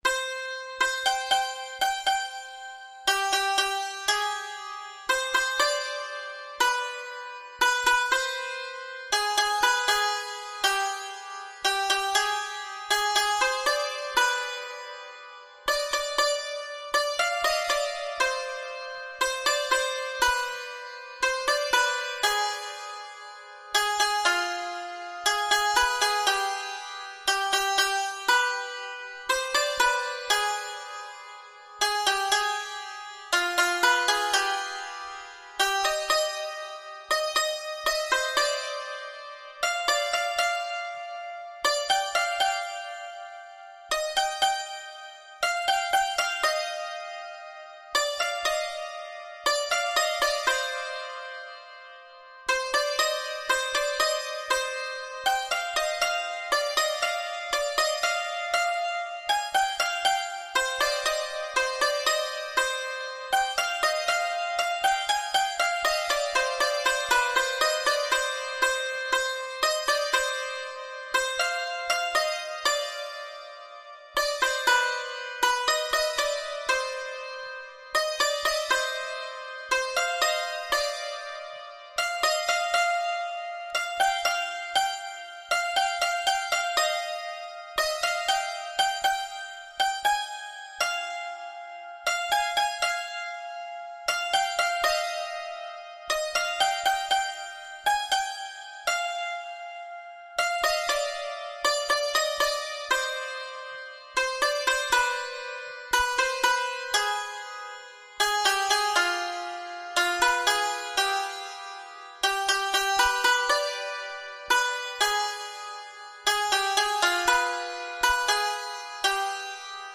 ساز : سنتور